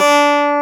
CLAV G3.wav